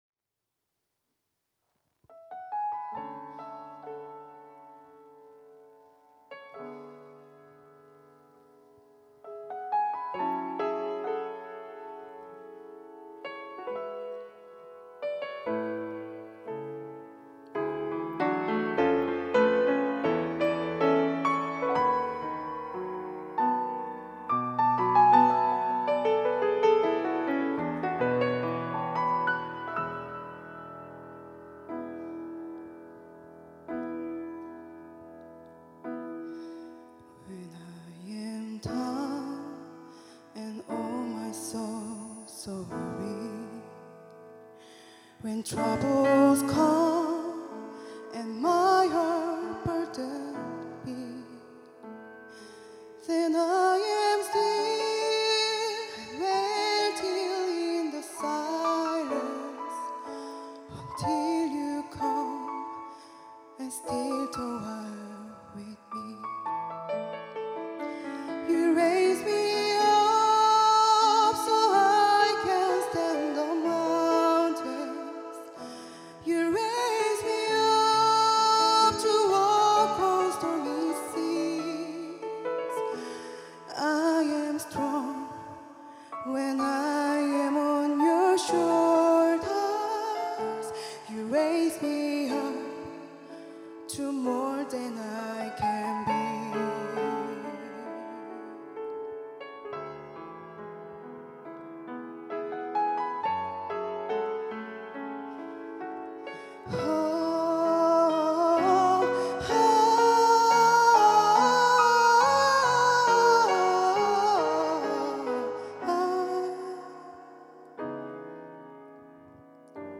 특송과 특주 - You raise me up